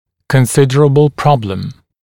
[kən’sɪdərəbl ‘prɔbləm][кэн’сидэрэбл ‘проблэм]существенная проблема, значительная проблема